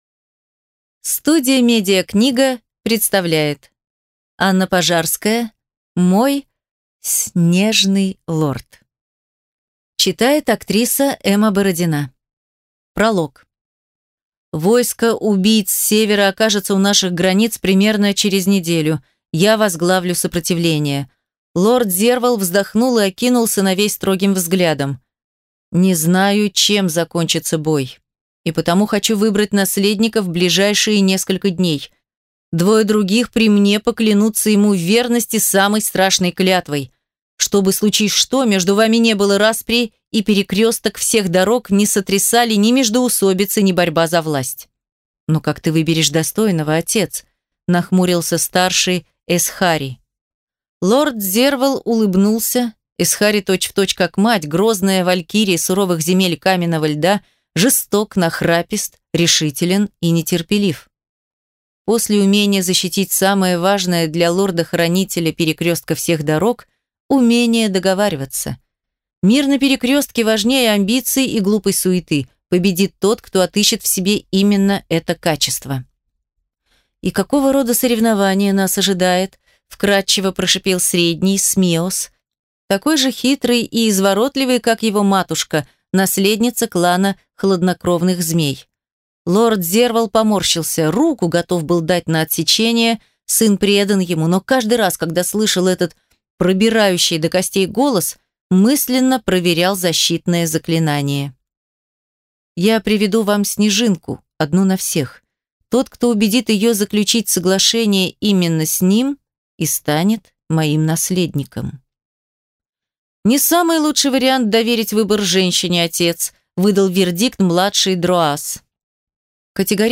Аудиокнига Мой (с)нежный лорд | Библиотека аудиокниг